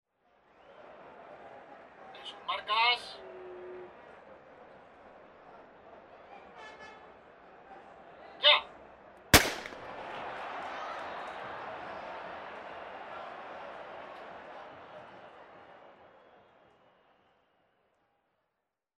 Salida de una carrera de atletismo
disparo
Sonidos: Gente
Sonidos: Acciones humanas
Sonidos: Deportes